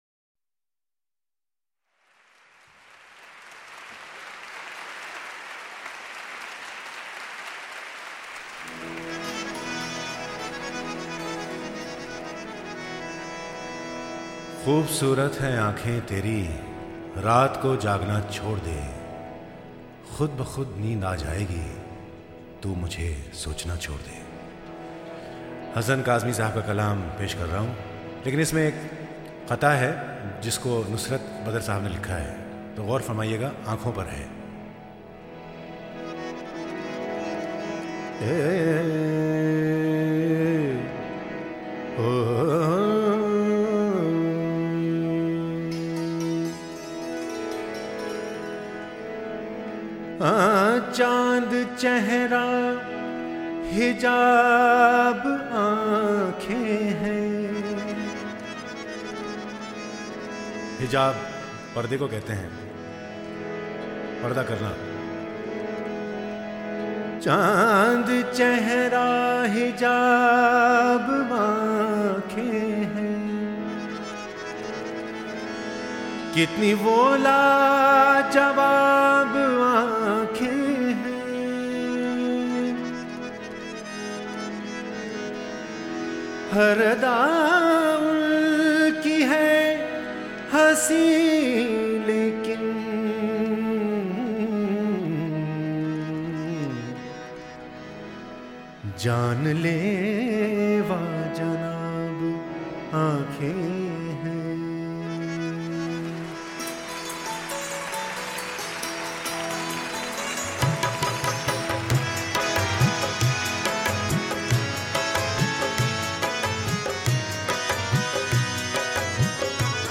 Ghazals
Live